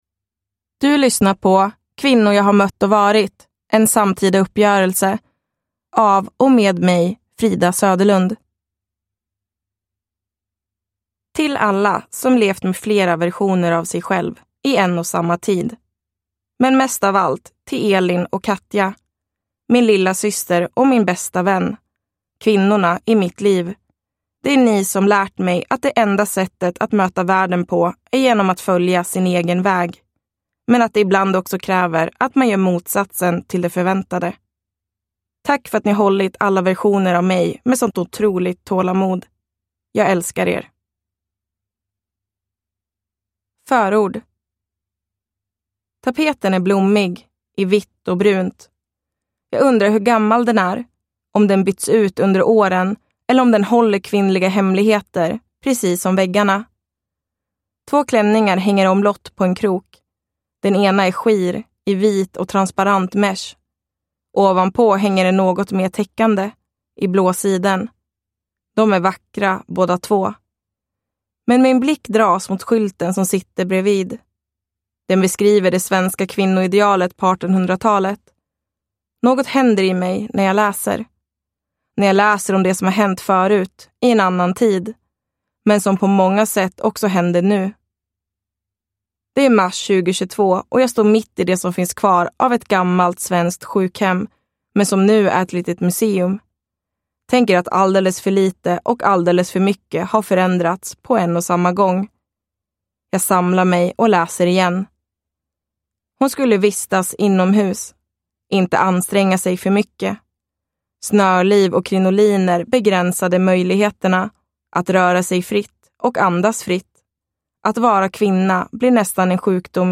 Kvinnor jag har mött och varit : en samtida uppgörelse – Ljudbok – Laddas ner